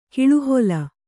♪ kiḷuhola